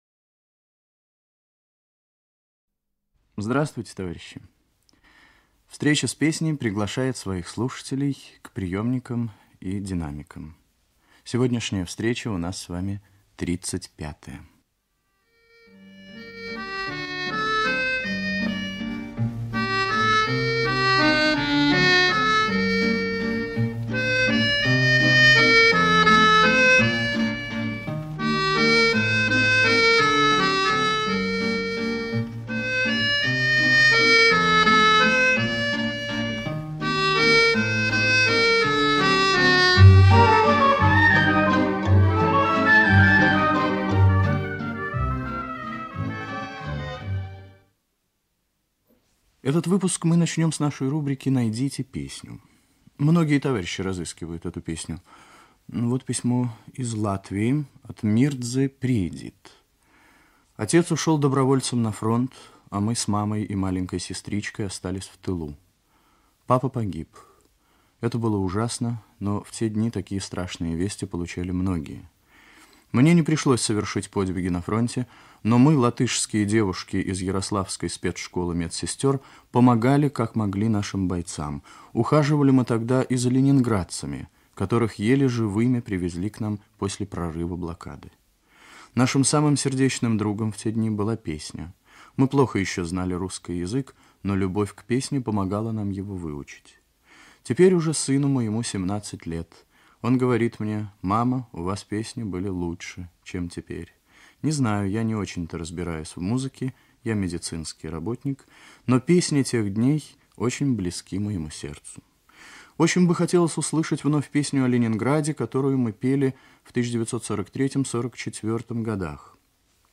Музыкальная заставка к передаче;